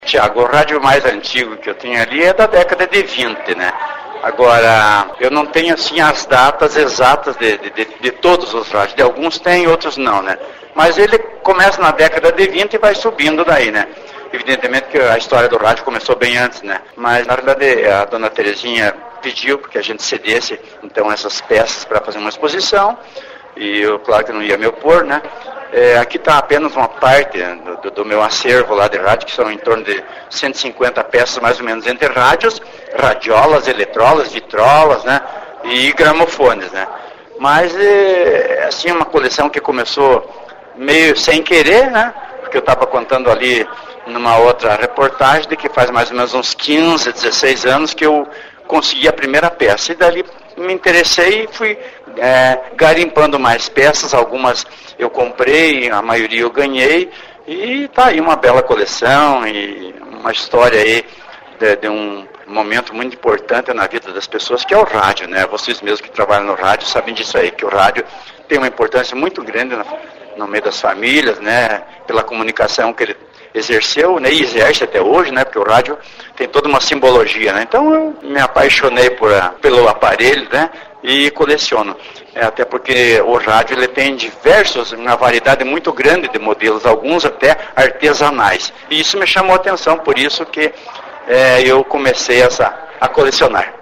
Como está reportagem fala de um dos veículos mais importantes da comunicação da sociedade, nada melhor do que as entrevistas serem no formato que muitas pessoas estão sempre acostumadas a ouvir nas ondas de Amplitude Modulada (AM) ou Frequência Modulada (FM).
O expositor e prefeito de Porto União Anízio de Souza, conta como surgiu a ideia de colecionara os aparelhos antigos de rádio e como ele conseguiu o primeiro aparelho.